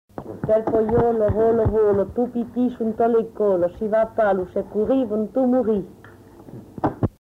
Aire culturelle : Périgord
Type de voix : voix de femme
Production du son : parlé
Classification : formulette enfantine